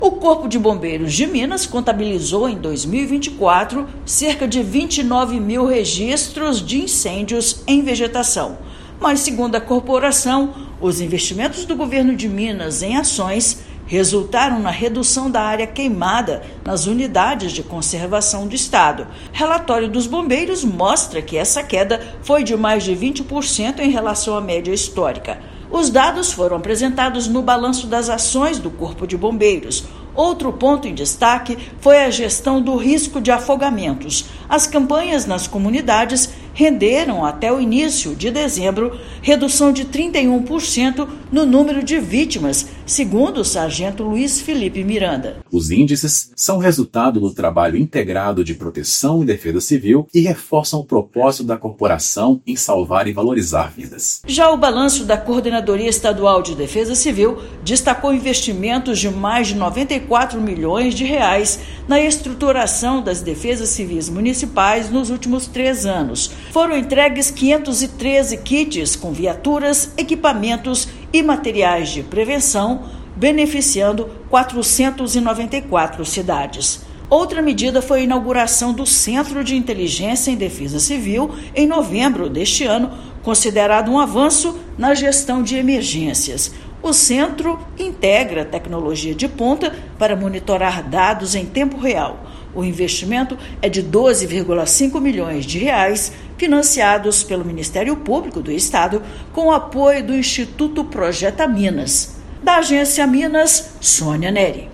Balanço de 2024 das Forças de Segurança destaca, ainda, inauguração do Centro de Inteligência em Defesa Civil (Cindec) e investimento recorde em gestão de emergências. Ouça matéria de rádio.